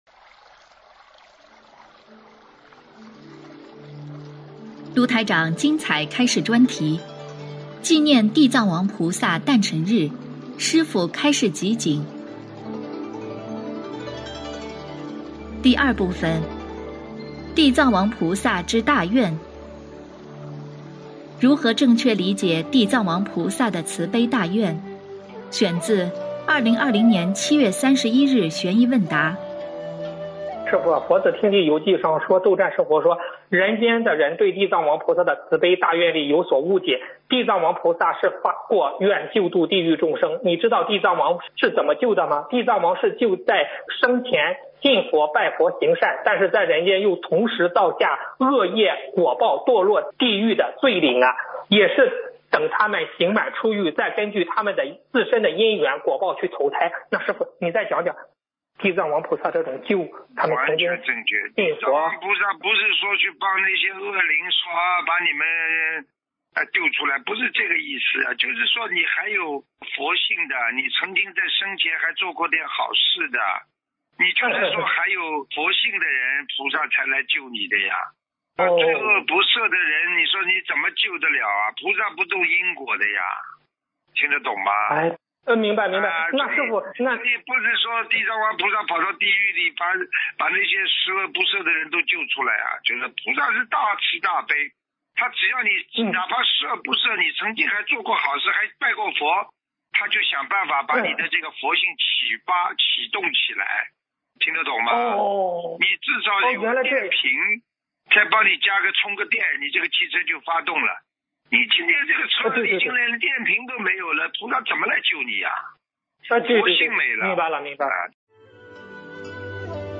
视频：128.【地藏王菩萨之“大愿”】—— 纪念地藏王菩萨诞辰日 师父开示集锦 - 专题分享 心灵净土